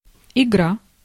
Ääntäminen
IPA : /ɡeɪm/